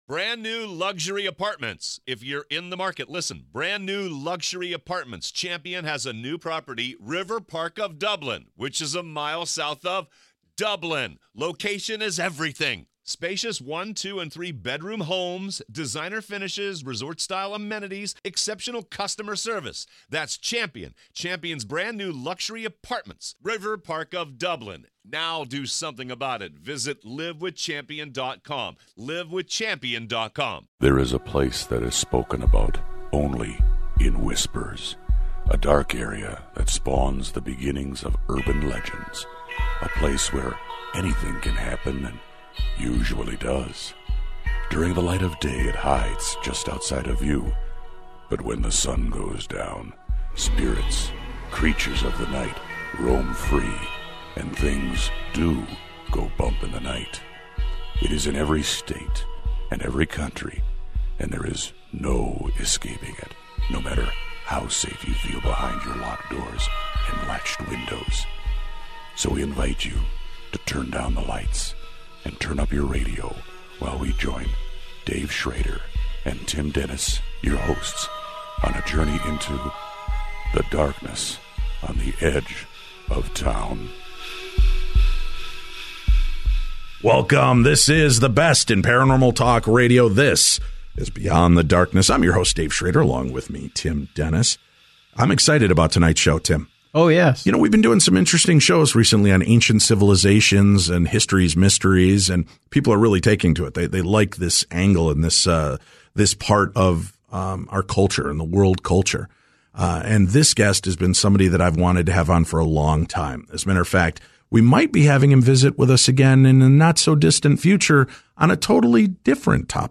Welcome, this is the best in paranormal talk radio this is beyond the darkness.